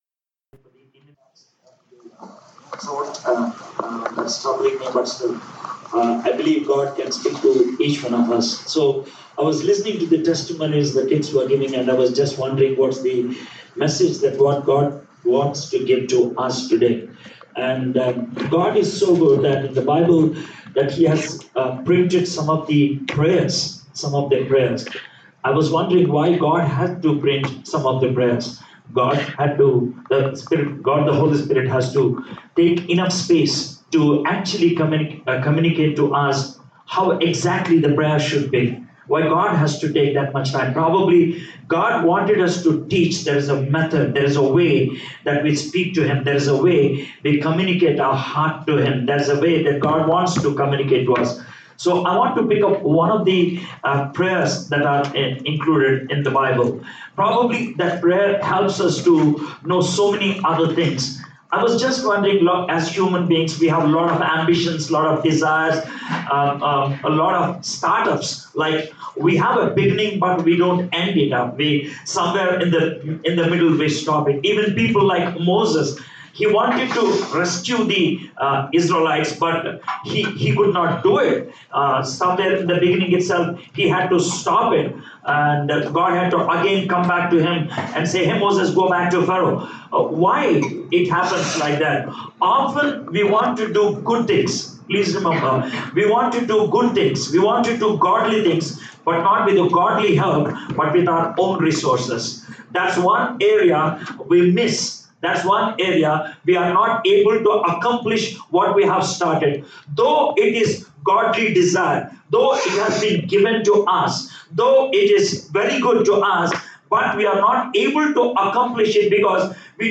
Guest speaker